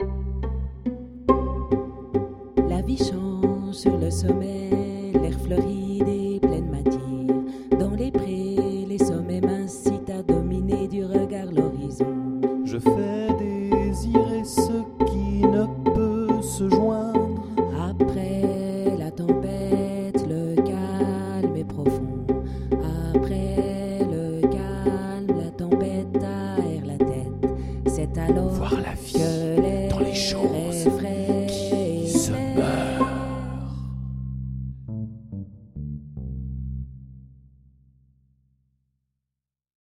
MartheMephistoPizzicato_1.mp3